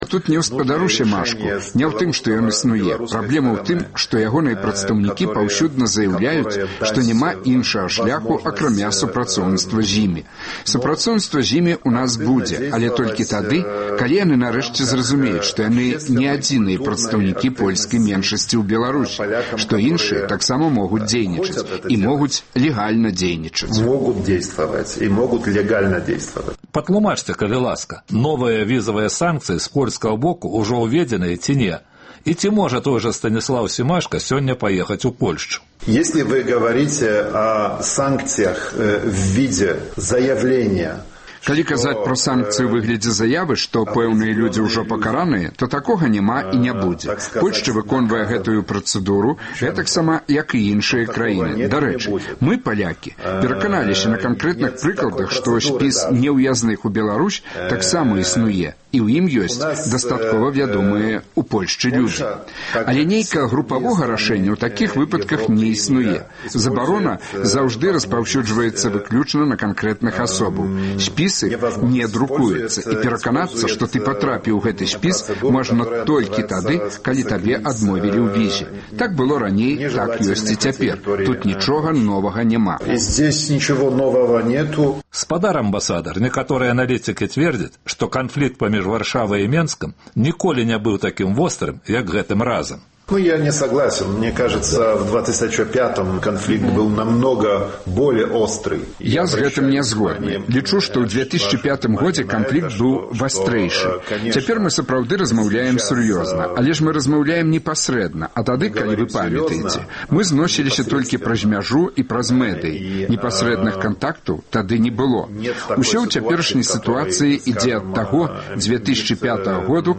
Амбасадар Польшчы ў Беларусі Генрык Літвін даў эксклюзіўнае інтэрвію беларускай службе Радыё Свабода.